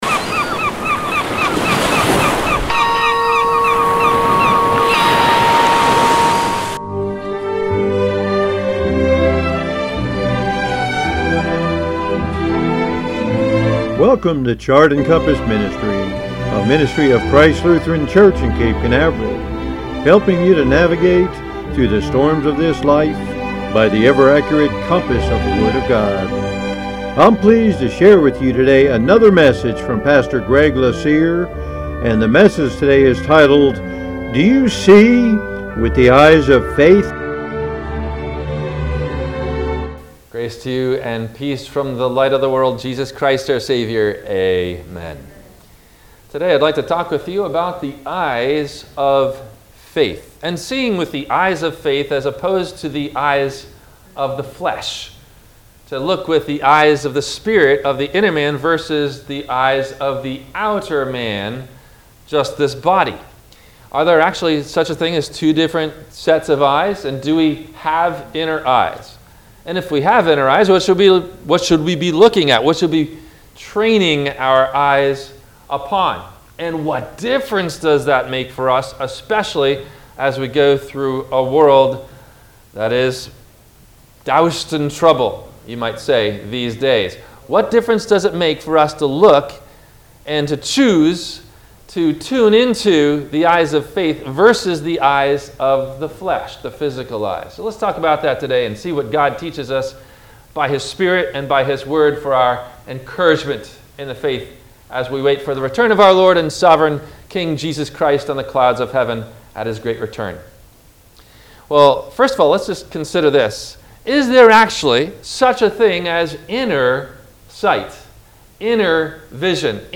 Do You See With The Eyes Of Faith? – WMIE Radio Sermon – August 07 2023